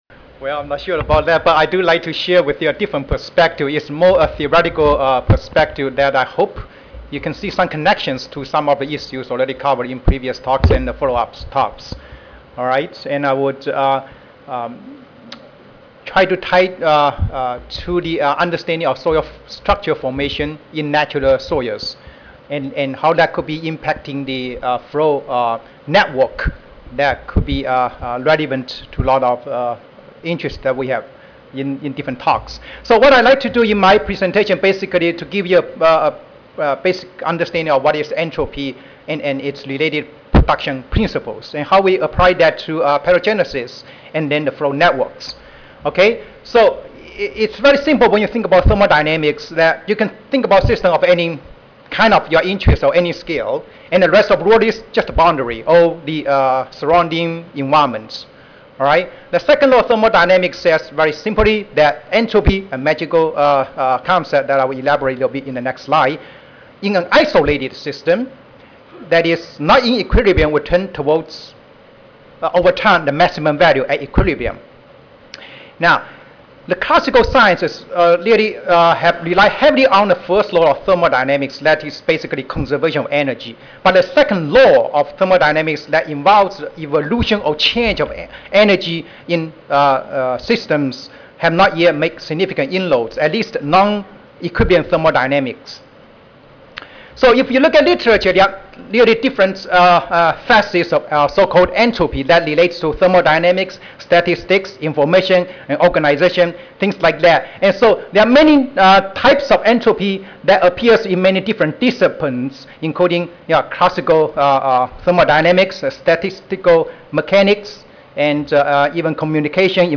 Pennsylvania State University Audio File Recorded presentation